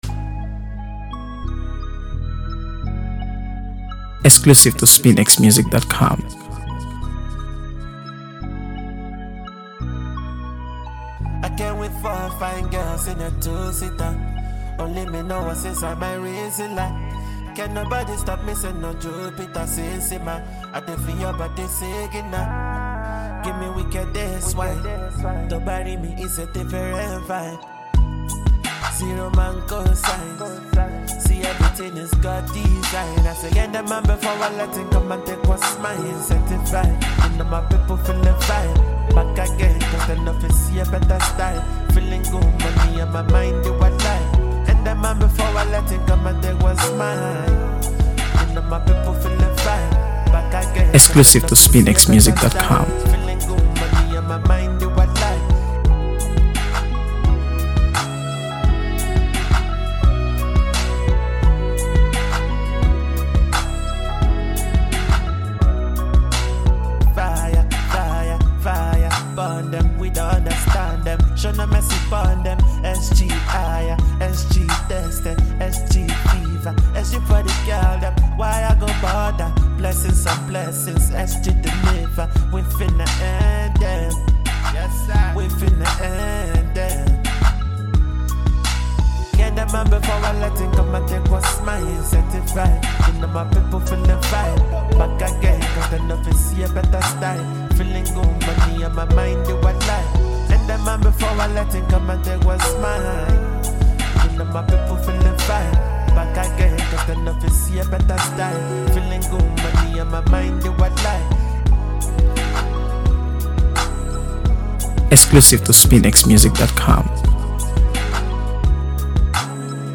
AfroBeats | AfroBeats songs
slow-burning anthem of purpose